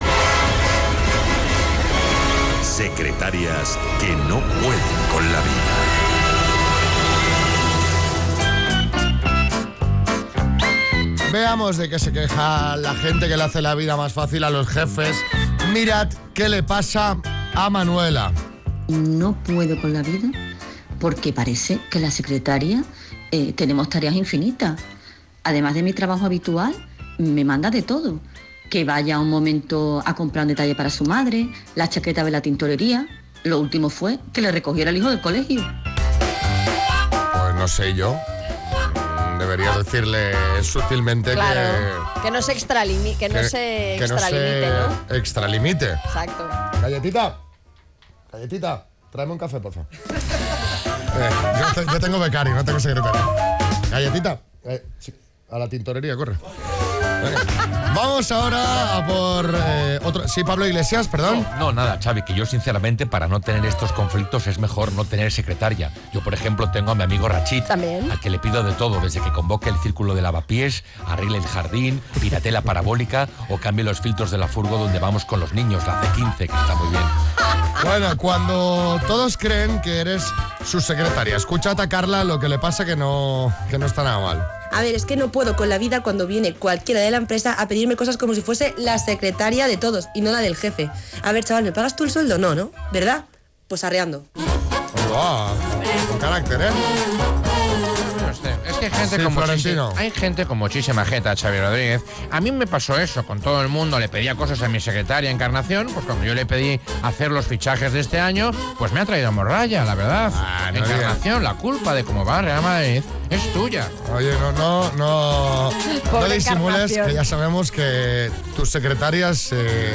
Esta mañana los micros del programa se han abierto a esas personas que hacen la vida más fácil a los jefes.